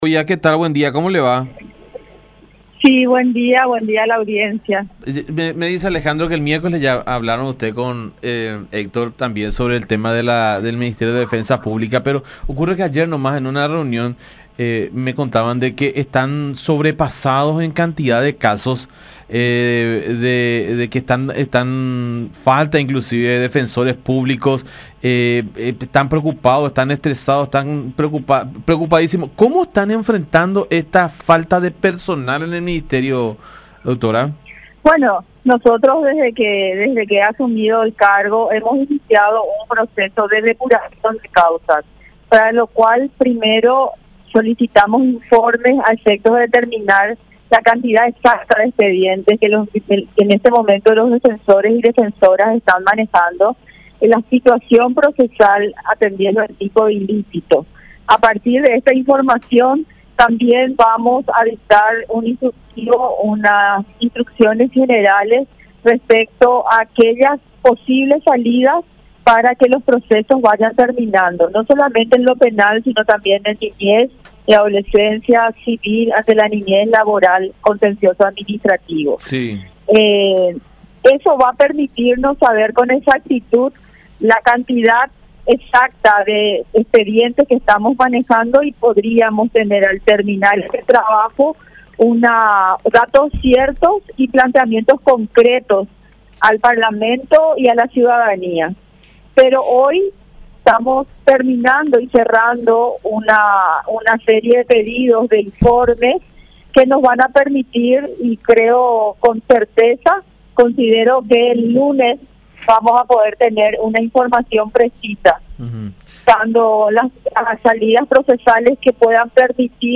Para mitigar esta situación, afirmó en comunicación con La Unión que desde que asumió en el cargo ha iniciado un proceso de depuración de casos.